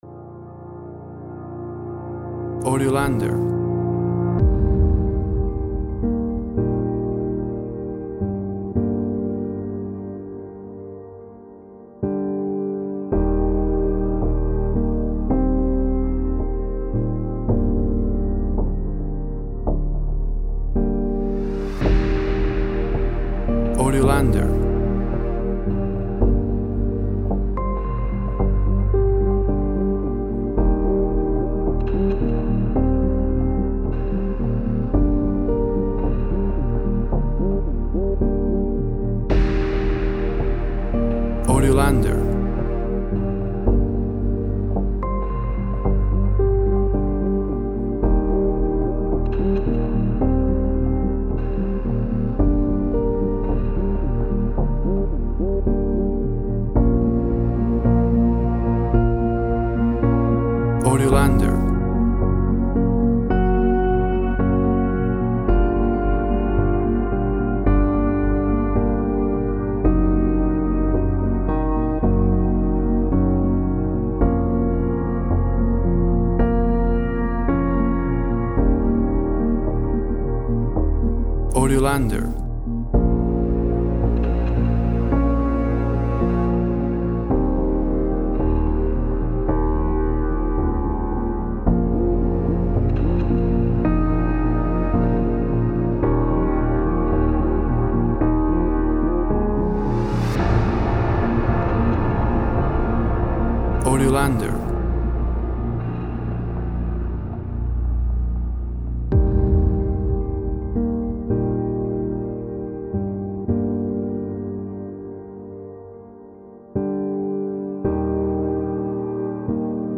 A sad minimal piano theme with some subtle arrangement.
Tempo (BPM) 110